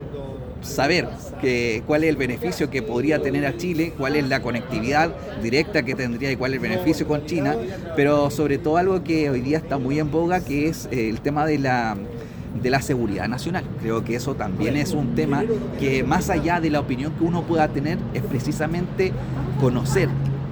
En conversación con BioBioChile desde España, el legislador independiente pro DC calificó la discusión como relevante, pero cuestionó la forma en que se ha abordado.